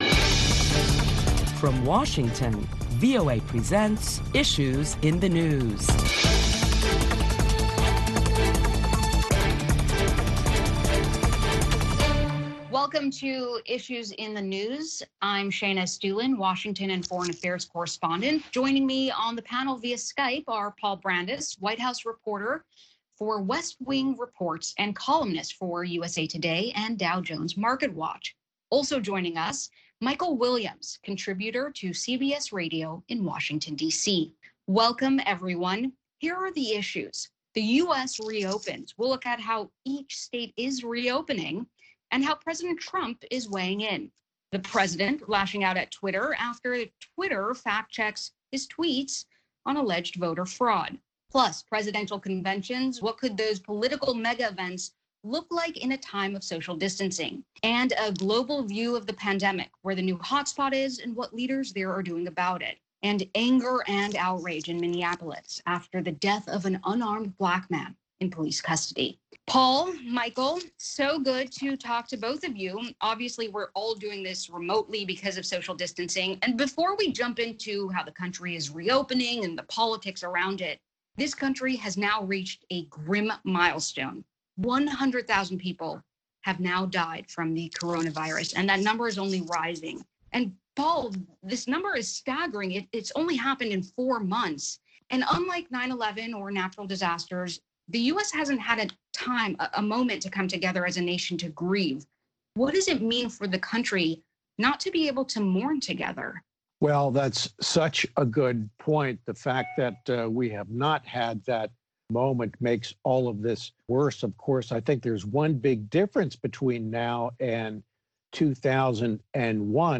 Join a panel of prominent Washington journalists as they deliberate the latest top stories that include the rise of the number of coronavirus infections and deaths in Brazil.